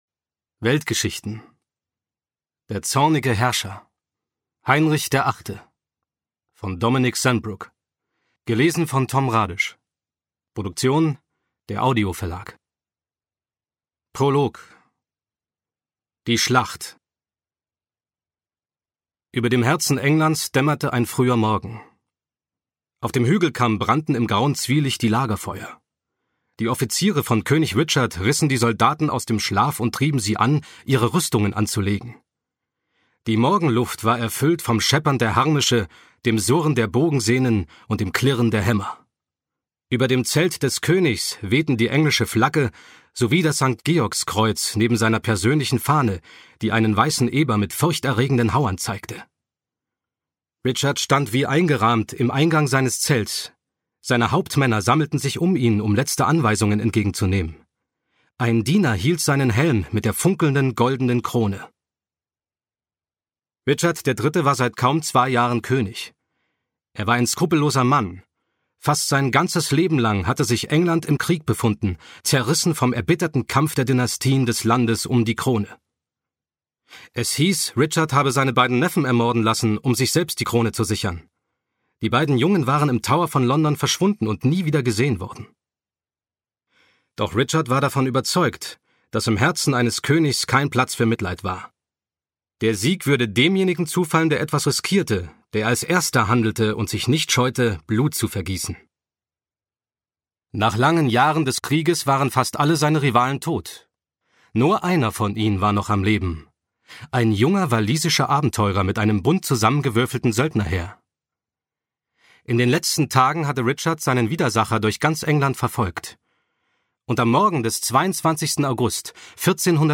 Schlagworte Anglikanische Kirche • Anna von Kleve • Anne Boleyn • Catherine Howard • Catherine Parr • Erasmus von Rotterdam • Erzählendes Sachbuch • geköpft • Geschichte • Heinrich der Achte • Hörbuch • Jane Seymour • Katharina von Aragon • kinder ab 10 • Kindersachbuch • Kinderwissen • Kirche Englands • König von England • Kronprinz • Macht • Neuerscheinung 2022 • Renaissance • Rosenkriege • Sachwissen für Kinder • Scheidung • Thronfolger • Tudors • Ungekürzt • Unterhaltungshistorik